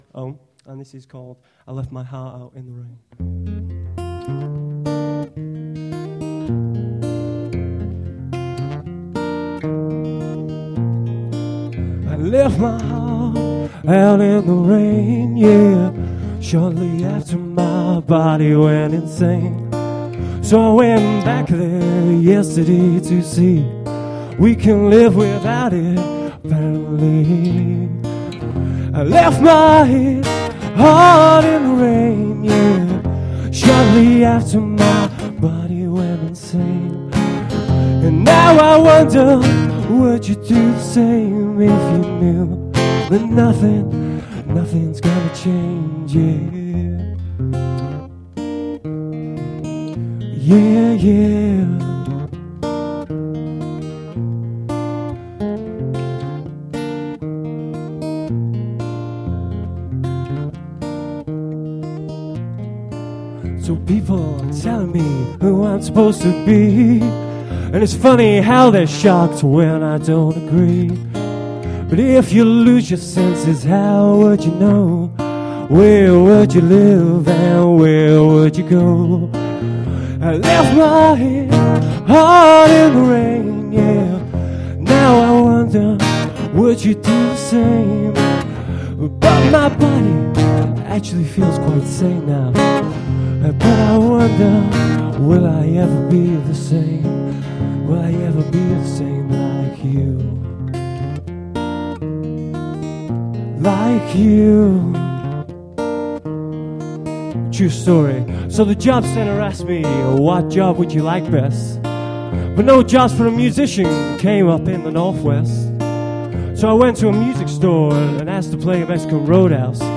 We4Poets Live at the IABF, Manchester
acoustic sets